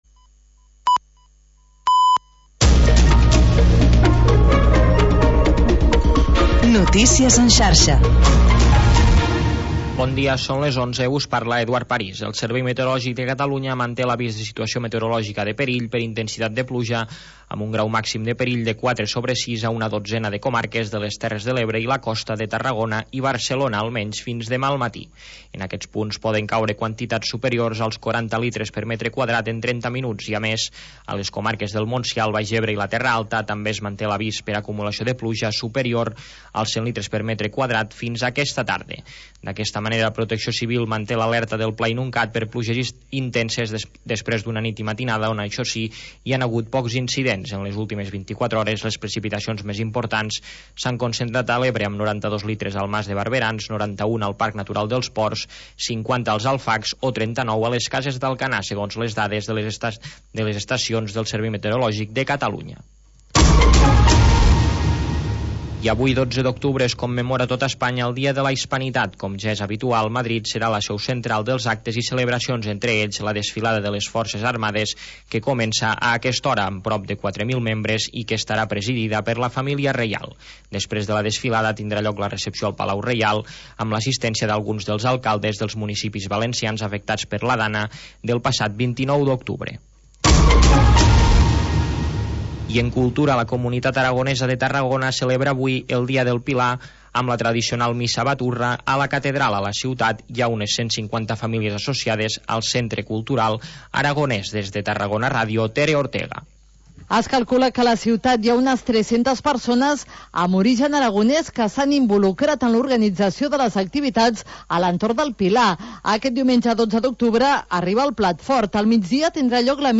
Un abecedari musical de grups d'havaneres que estan en actiu i que mantenen viva aquesta tradició,